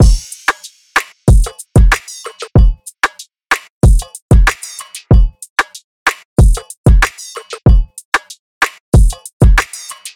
DRUM LOOPS
Peak (188 BPM – Em)